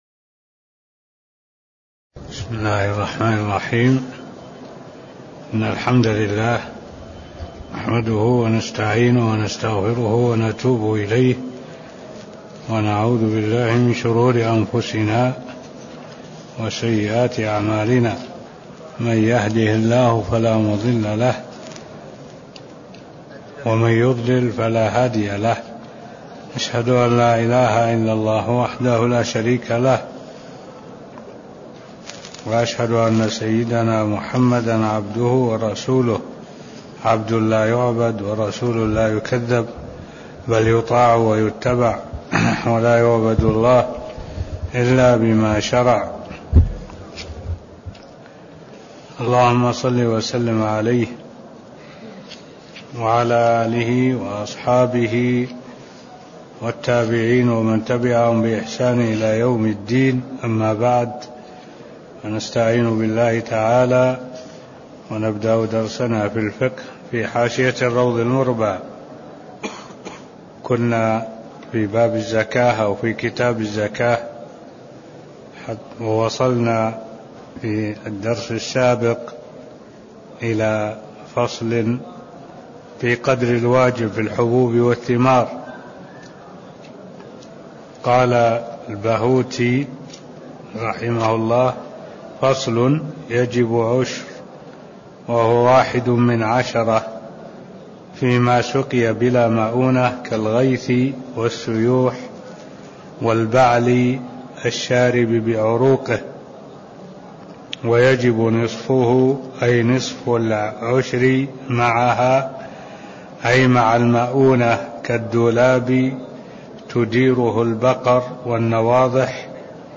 تاريخ النشر ١ صفر ١٤٢٧ هـ المكان: المسجد النبوي الشيخ: معالي الشيخ الدكتور صالح بن عبد الله العبود معالي الشيخ الدكتور صالح بن عبد الله العبود فصل -يجب العشر (002)